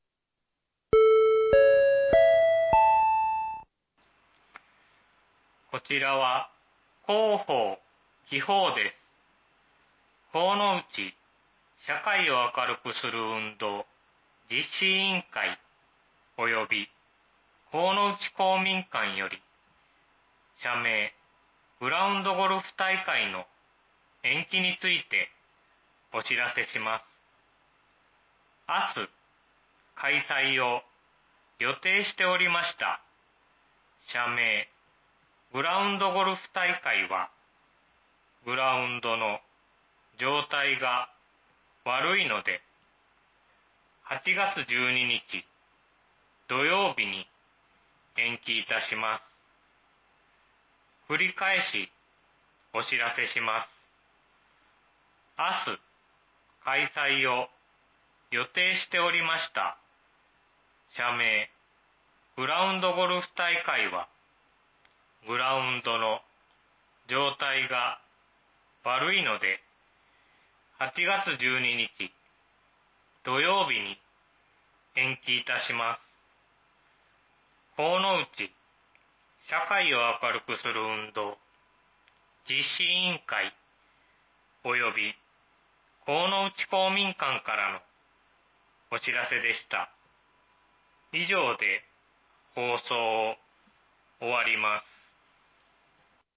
明日、開催を予定しておりました、社明グラウンドゴルフ大会は、グラウンドの状態が悪いので、８月１２日（土）に延期いたします。 （神内地区のみの放送です。）
放送音声